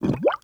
SINGLE BUB0B.wav